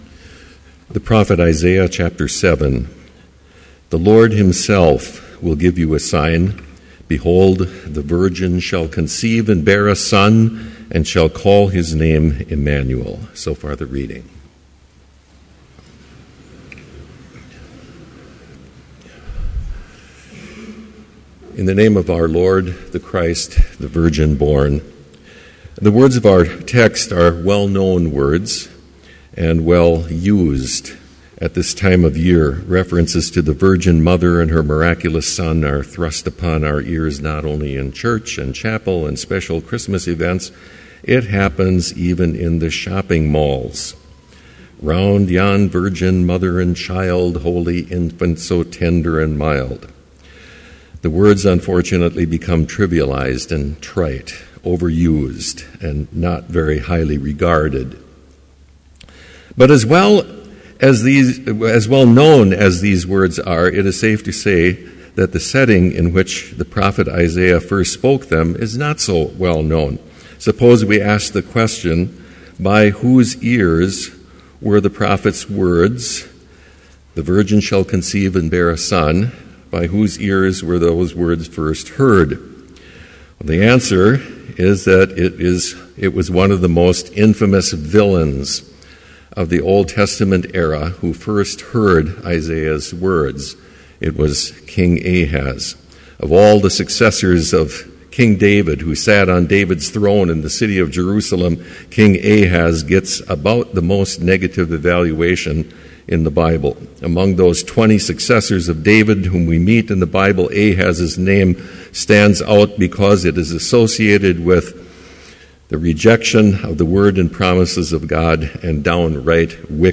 Complete service audio for Chapel - December 9, 2013